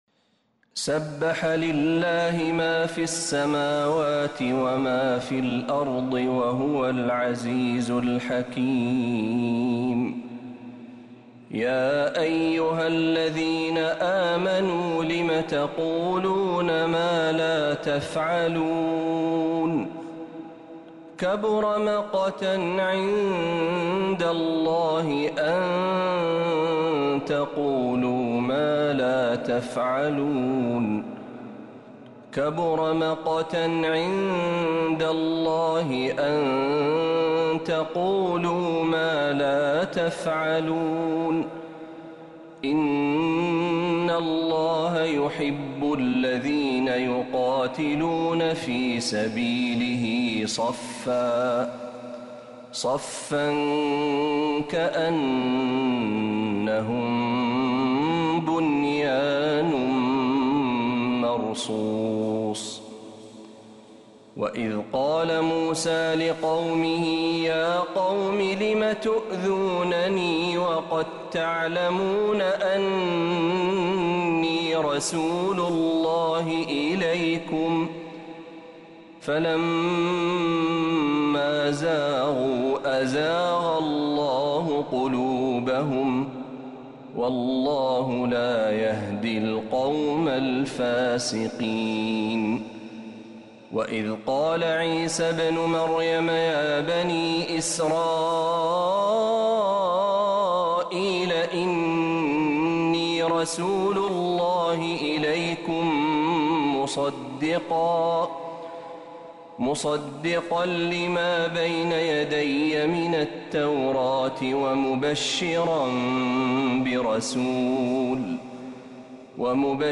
سورة الصف كاملة من عشائيات الحرم النبوي للشيخ محمد برهجي | جمادى الآخرة 1446هـ > السور المكتملة للشيخ محمد برهجي من الحرم النبوي 🕌 > السور المكتملة 🕌 > المزيد - تلاوات الحرمين